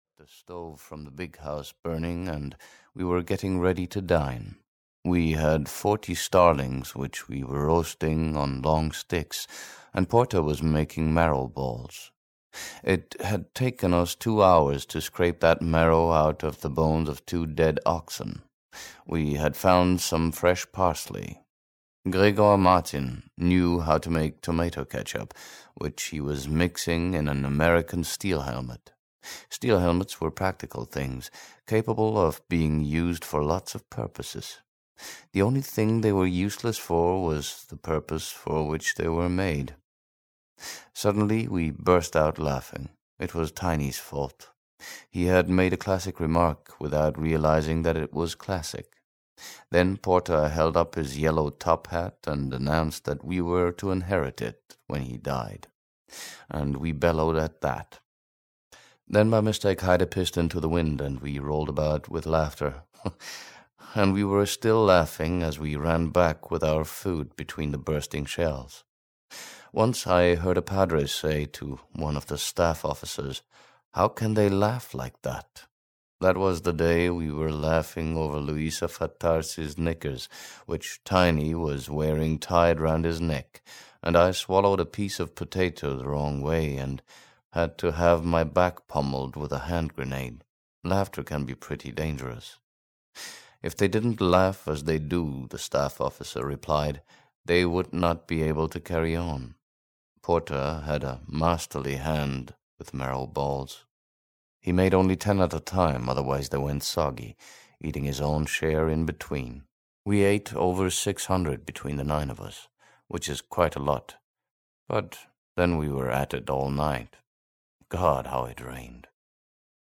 Monte Cassino (EN) audiokniha
Ukázka z knihy